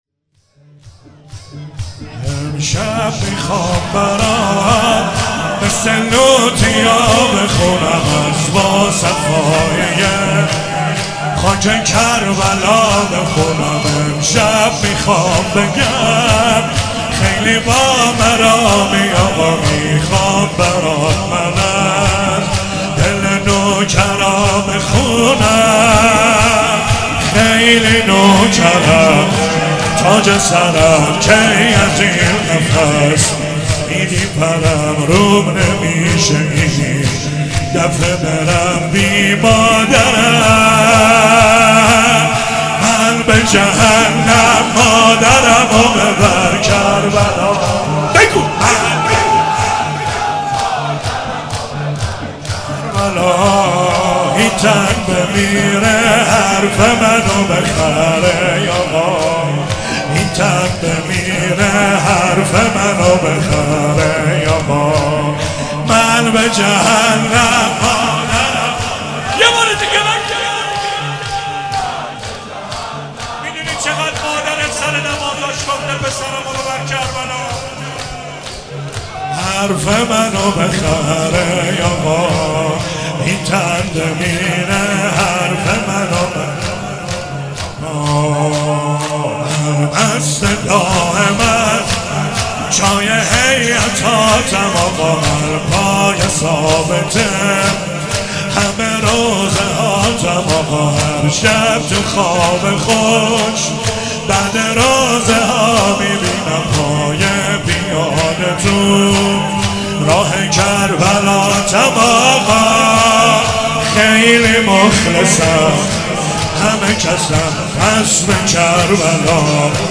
شهادت حضرت زینب سلام الله علیها